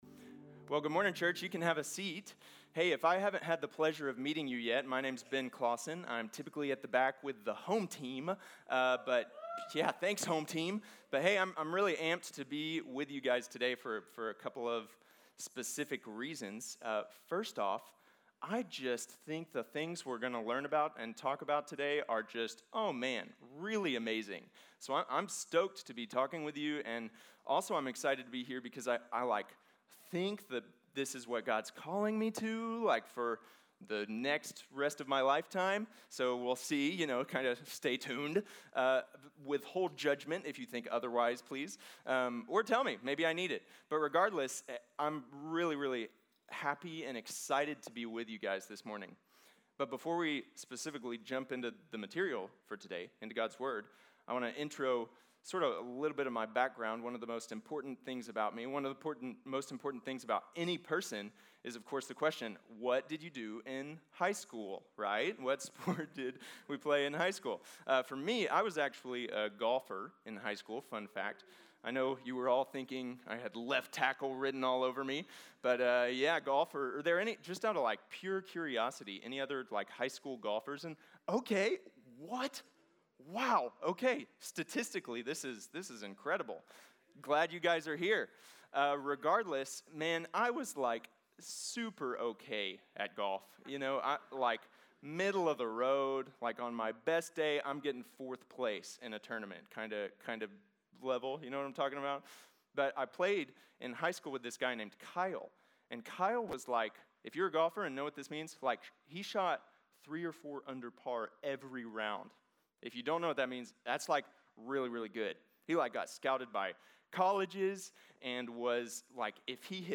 Reclaiming Meditation | Sermon | Grace Bible Church